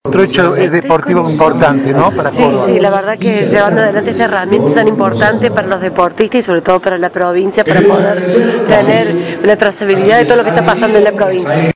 Córdoba y la modernización de la salud deportiva. Voces de los protagonistas de una Jornada Histórica en el Polo Deportivo Kempes.
Audio del concepto de la Señora Vice Gobernadora de Córdoba, MYRIAN PRUNOTTO: